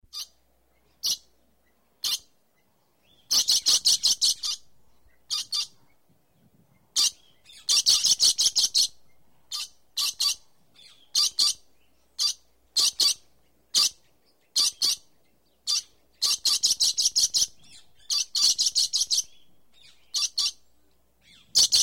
Roseate Cockatoo-sound-HIingtone
roseate-cockatoo.mp3